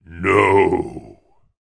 game_over.ogg